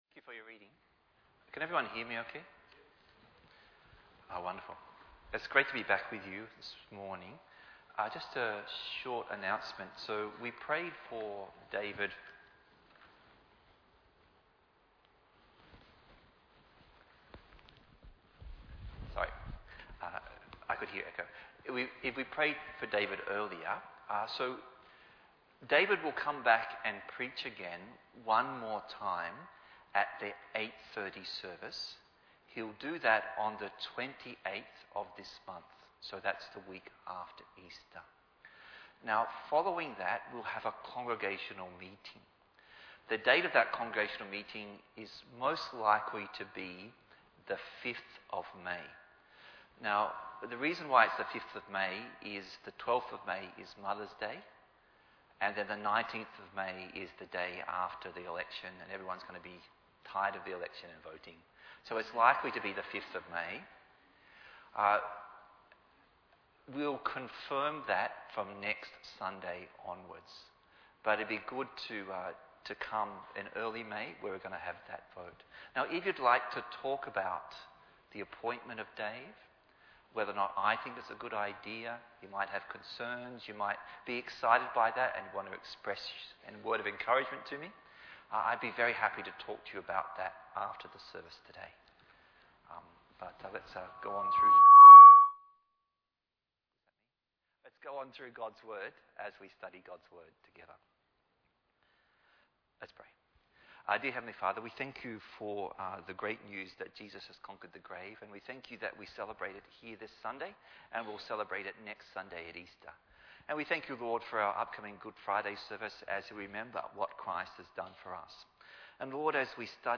Bible Text: Mark 11:1-19 | Preacher